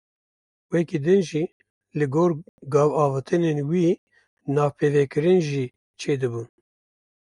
Pronounced as (IPA)
/ɡoːr/